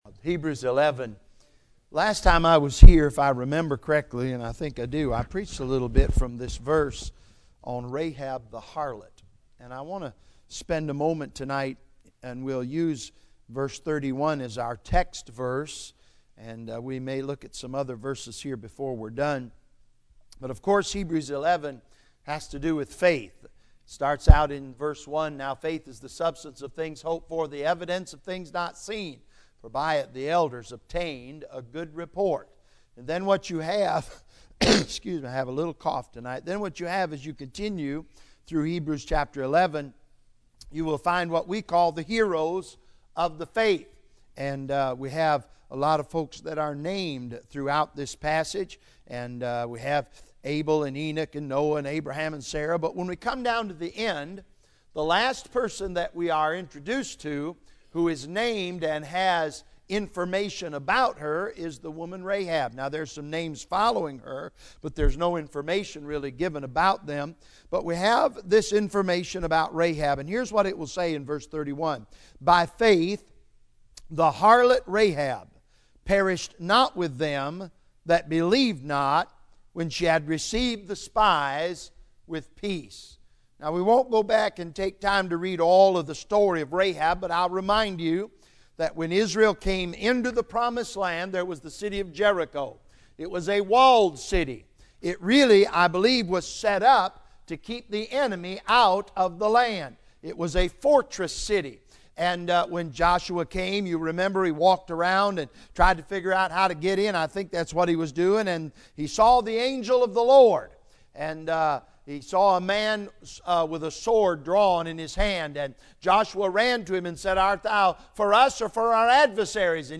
Bible Text: Hebrews 11 | Preacher